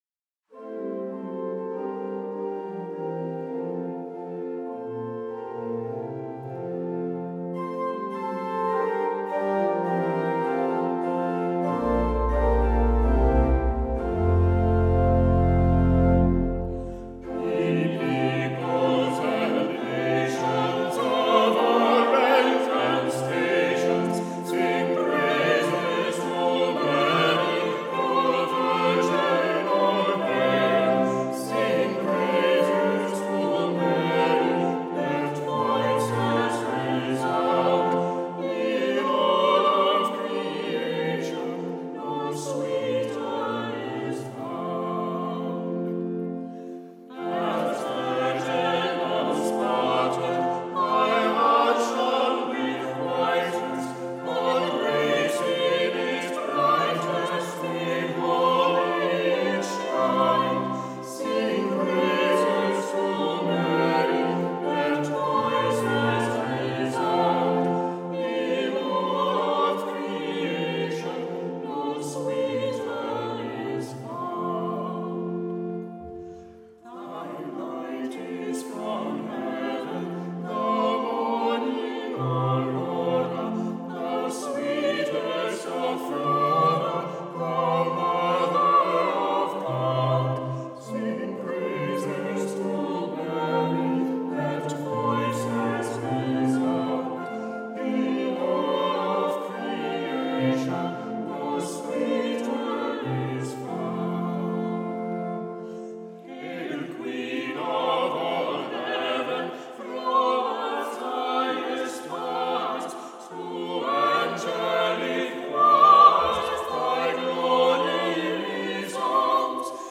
2018: St. Joseph Church, Needham
Choir: